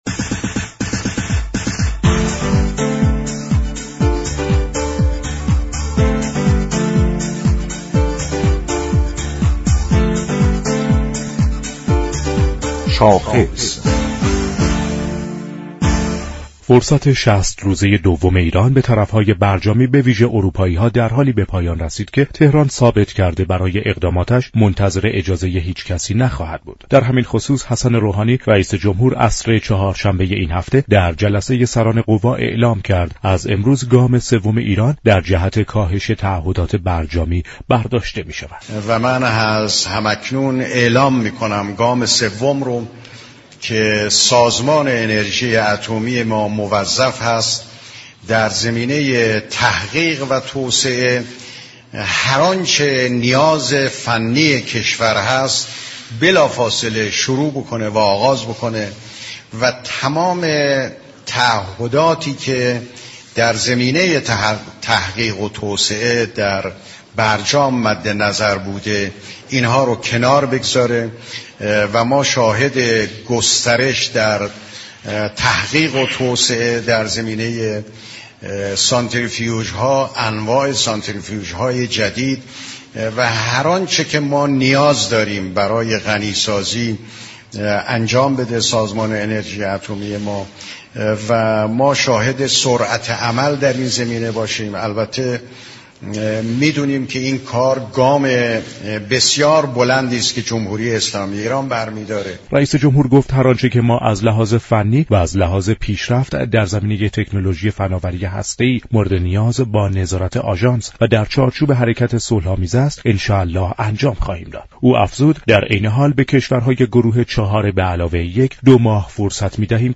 به گزارش شبكه رادیویی ایران، كمال دهقانی فیروزآبادی عضو كمیسیون امنیت ملی و سیاست خارجی مجلس در گفت و گو با برنامه «رویدادهای هفته» درباره گام سوم ایران در جهت كاهش تعهدات برجامی گفت: ایران با اقدام اخیر می خواهد به طرف های مذاكره این را بگوید كه تعهدات دو طرفه است، اگر یك طرف مذاكره پایبند به تعهداتش نباشد طرف دیگر نیز می تواند تعهدات خود را عملی نسازد.